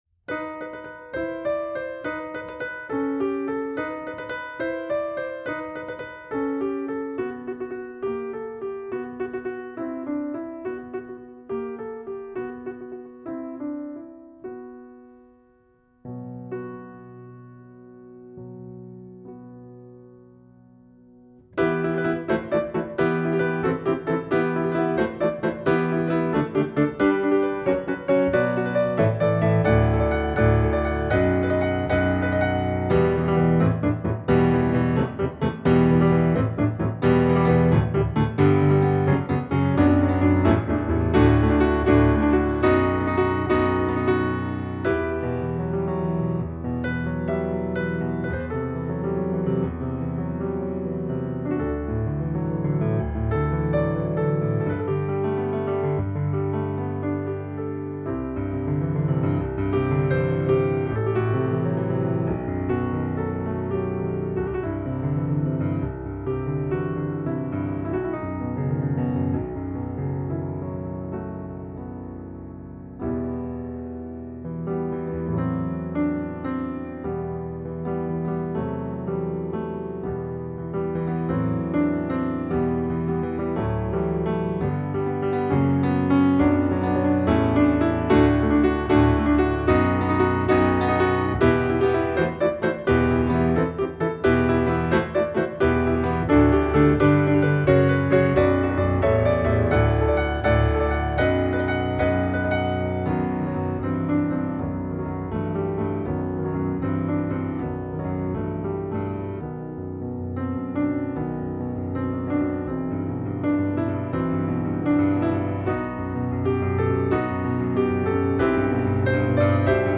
野性的袒露,灵魂的纯粹,斗牛士的柔情,应有尽有